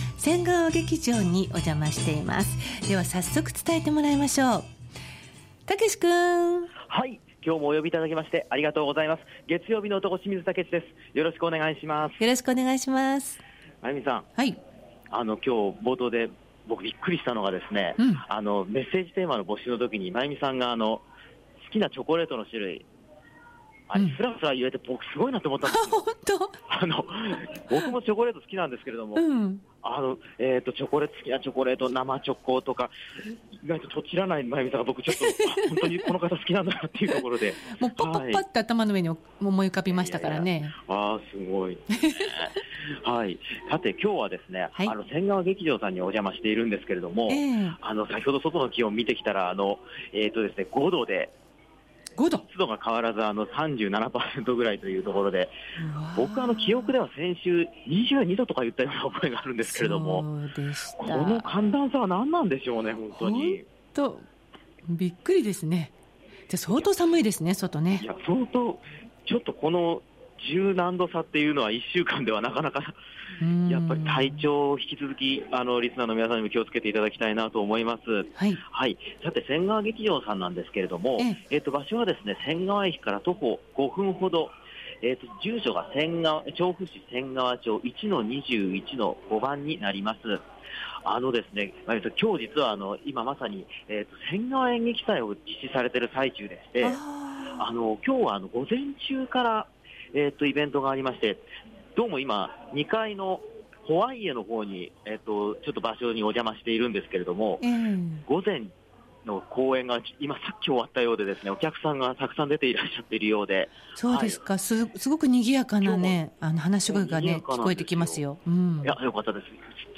先週と打って変わって、寒空の下からお届けした本日の街角レポートは、「せんがわ劇場」さんからのレポートです！！